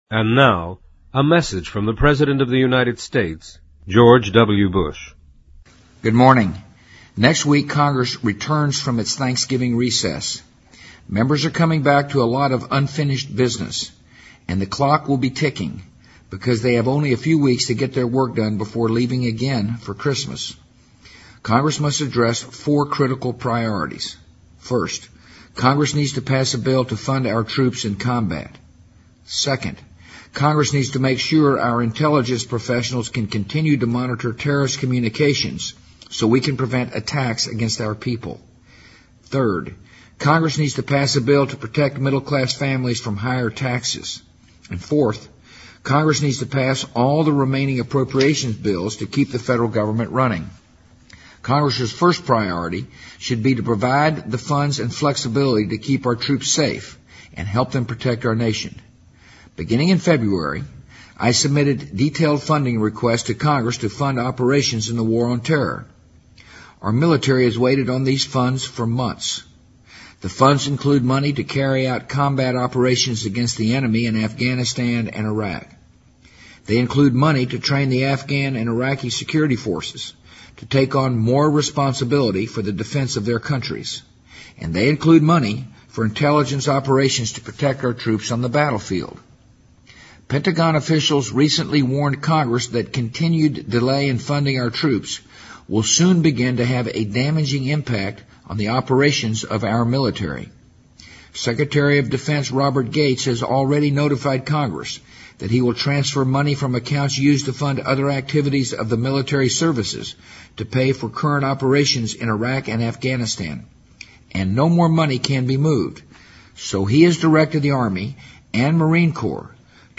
【美国总统电台演说】2007-12-01 听力文件下载—在线英语听力室